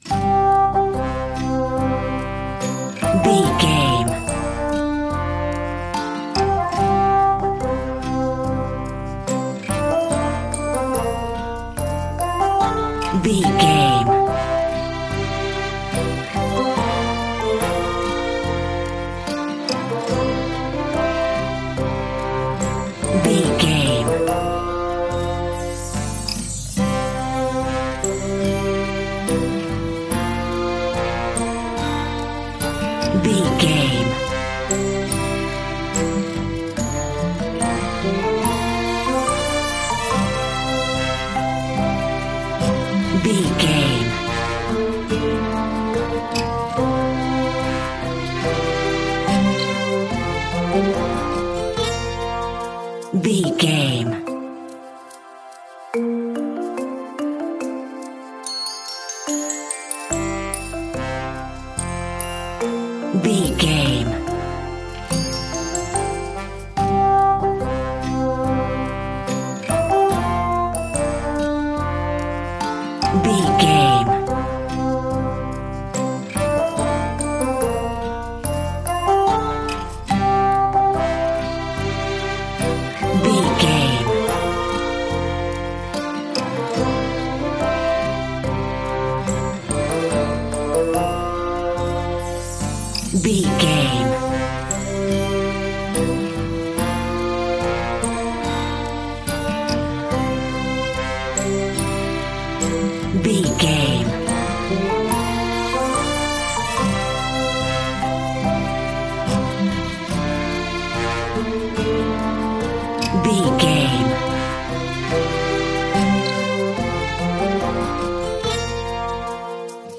Mixolydian
Slow
calm
dreamy
joyful
peaceful
acoustic guitar
bassoon
brass
sleigh bells
synthesiser
violin
strings
playful
soft